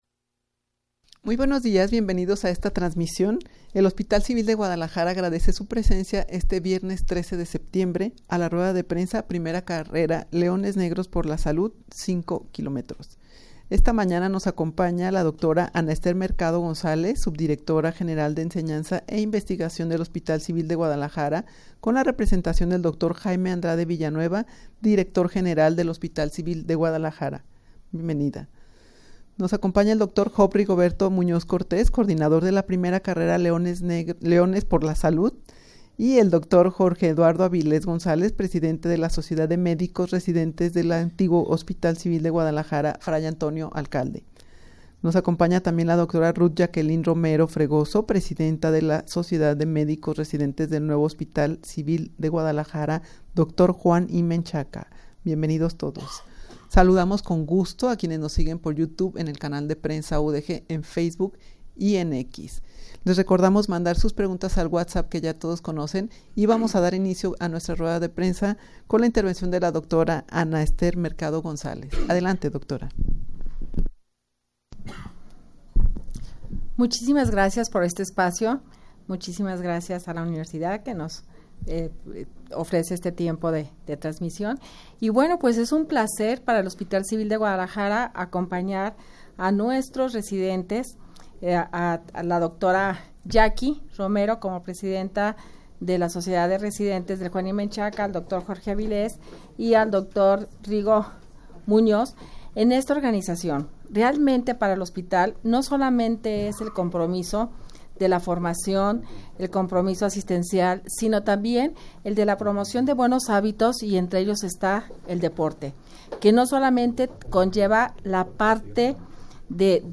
rueda-de-prensa-primera-carrera-leones-por-la-salud-5k.mp3